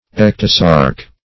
Search Result for " ectosarc" : The Collaborative International Dictionary of English v.0.48: Ectosarc \Ec"to*sarc\, n. [Ecto- + Gr.
ectosarc.mp3